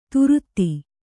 ♪ turutti